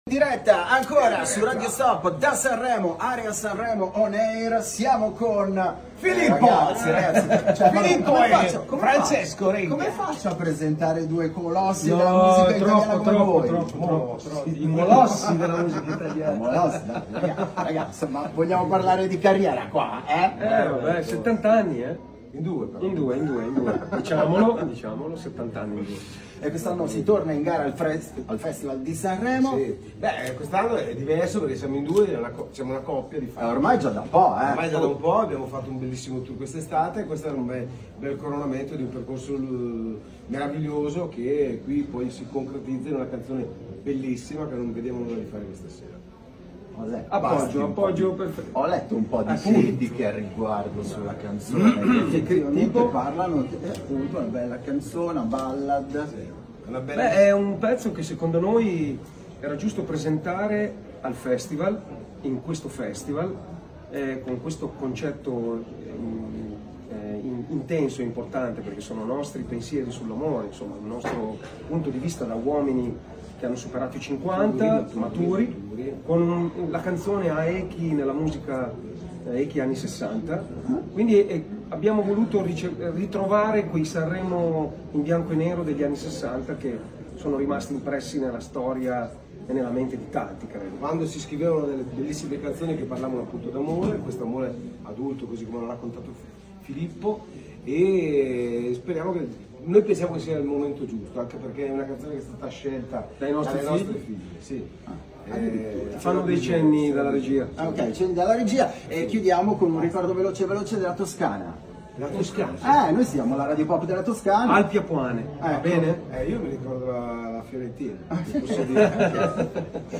Festival di Sanremo con Radio Stop!
Radio Stop – Intervista a RENGA E NEK
Intervista-a-RENGA-E-NEK.mp3